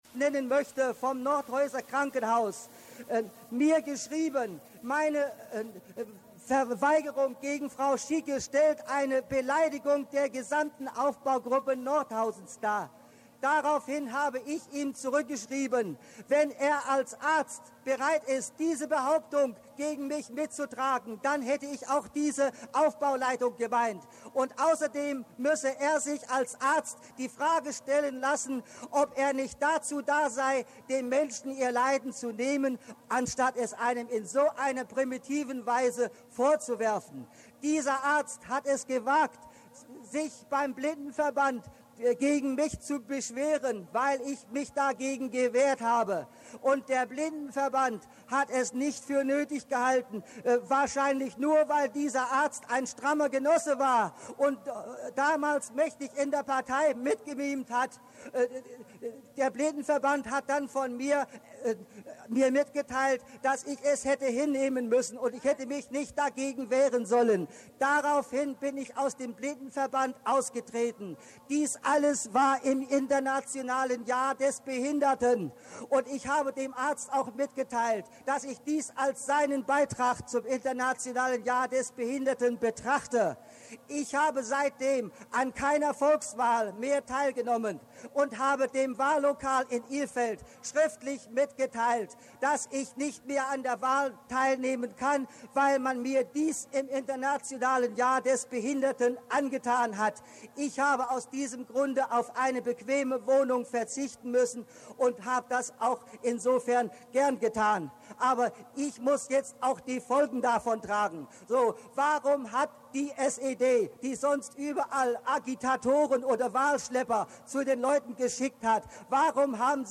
Demo auf dem August-Bebel-Platz am 28. November 1989